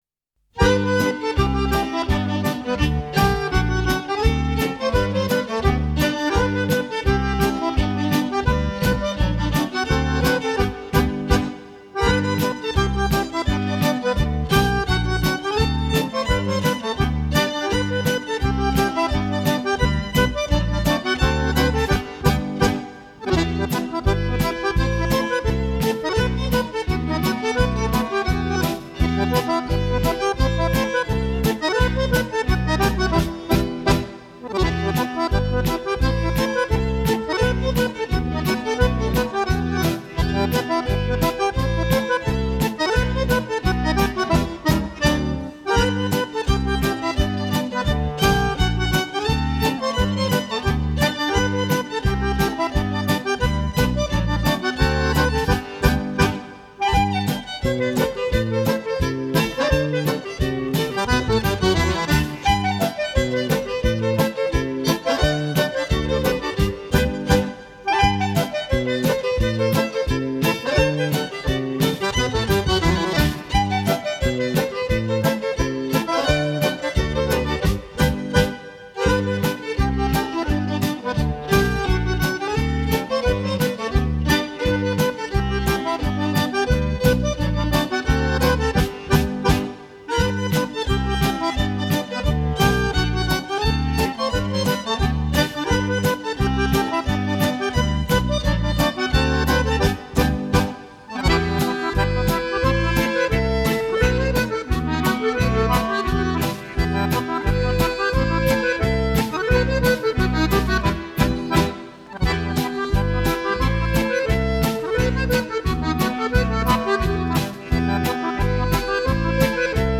Genre: Easy Listening, Instrumental